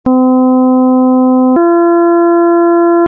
ἀρχεῖο ἤχου Νη-Γα· ὁ Νη στὰ 256Hz]
Νη-Γα = 4/3
Ni-Ga_256Hz.mp3